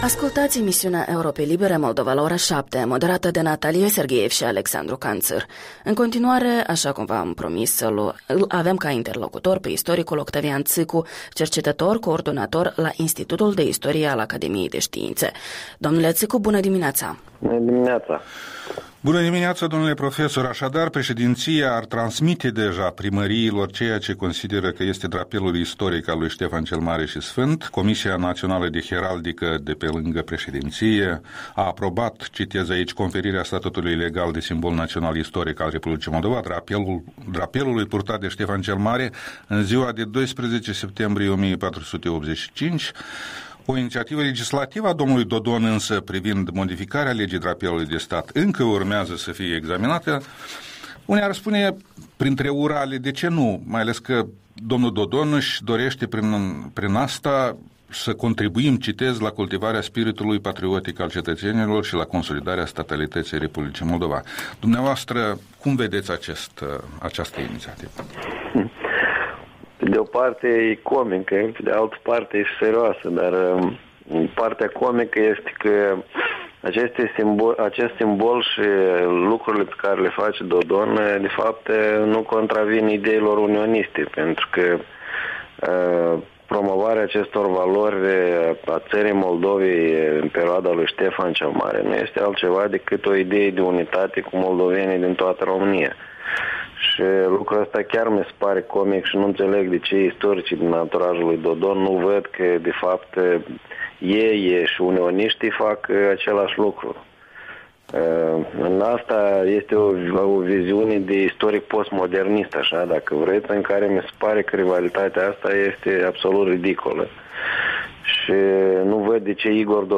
Interviul dimineții cu cercetătorul-coordonator la Institutul de Istorie al Academiei de Ştiinţe pe tema inițiativei președintelui Dodon referitoare la așa-numitul drapelul istoric al lui Ștefan cel Mare.